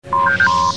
Category: Sound FX   Right: Personal